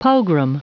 Prononciation du mot pogrom en anglais (fichier audio)
Prononciation du mot : pogrom